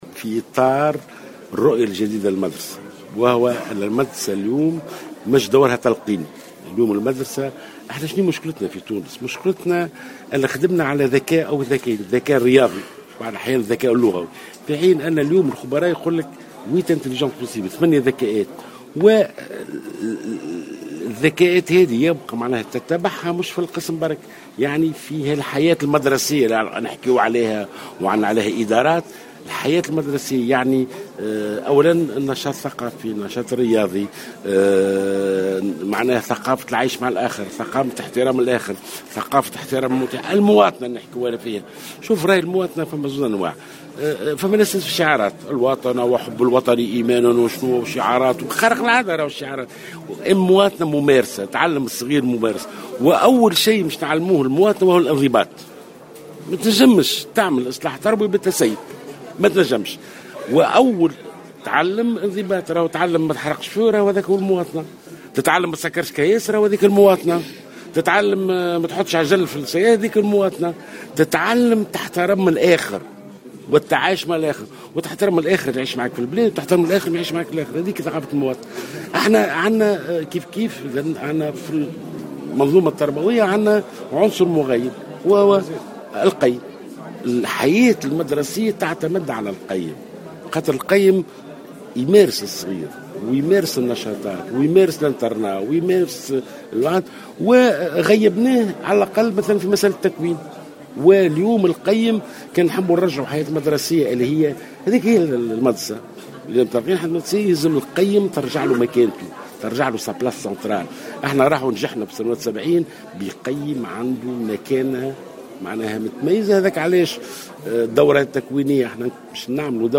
وأشار جلول، في تصريح للجوهرة أف أم، لدى إشرافه اليوم السبت في سوسة على اختتام ورشة تكوينية لفائدة القيّمين العامين والقيّمين، إلى "التغييب" الذي يتعرض له القيّم في المنظومة التربوية التونسية على رغم من أن الحياة المدرسية تعتمد بشكل كبير على دوره داخل المؤسسة التربوية، مؤكدا على أهمية إرجاع مكانته المحورية.